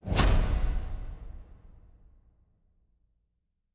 Special Click 04.wav